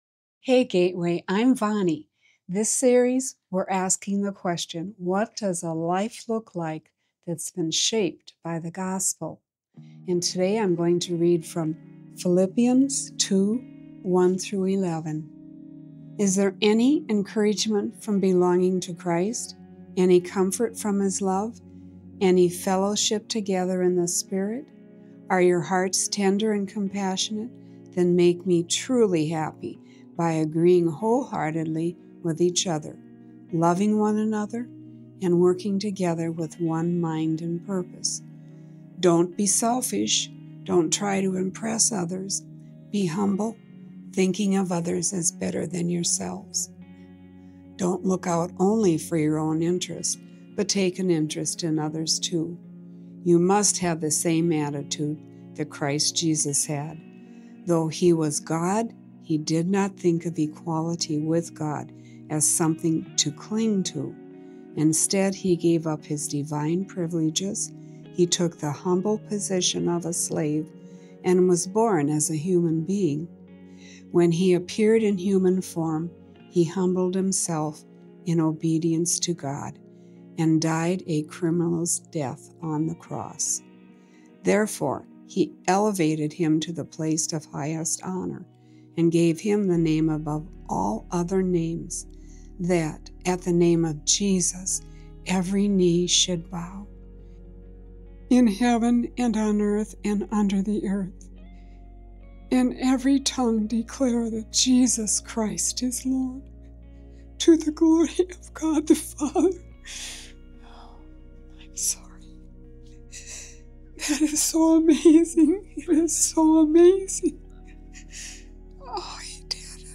Unity-from-Humility-Sermon-5.12.25.m4a